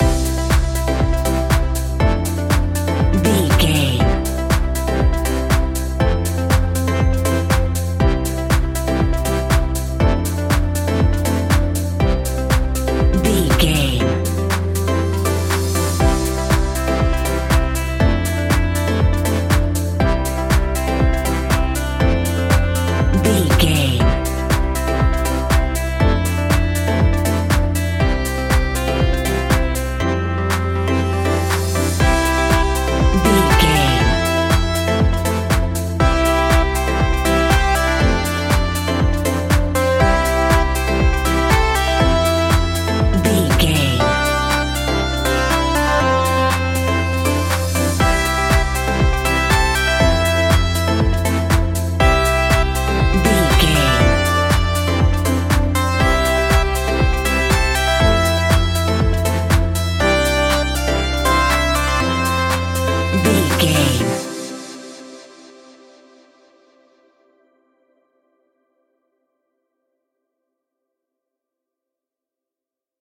Ionian/Major
groovy
dreamy
smooth
drum machine
synthesiser
funky house
deep house
nu disco
upbeat
funky guitar
wah clavinet
synth bass